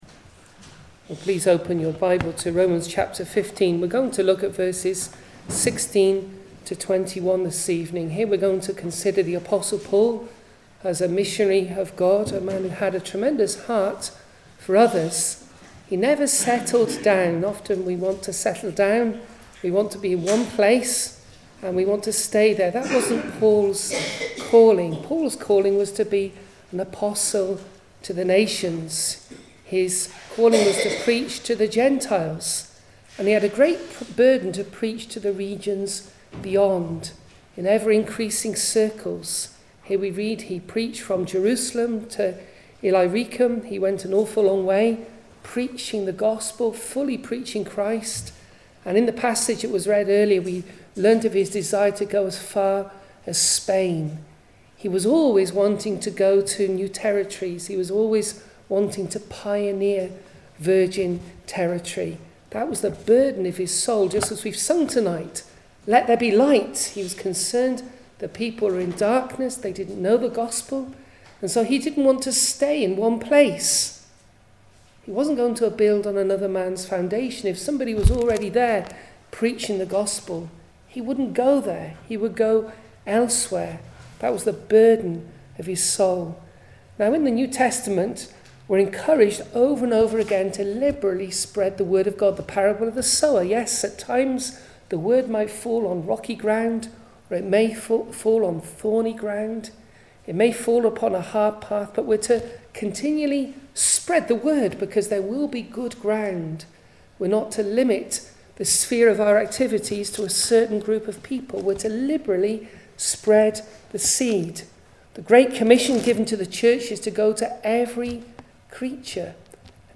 Passage: Romans 15:14-29, 2 Corinthians 10:7-18 Service Type: Sunday Evening